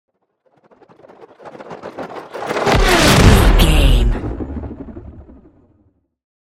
Dramatic chopper whoosh to hit
Sound Effects
Atonal
dark
futuristic
intense
tension
woosh to hit